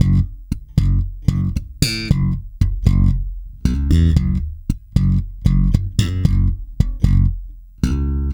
-JP THUMB F#.wav